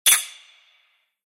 Golpe de crótalos sordo
percusión
crótalo
sordo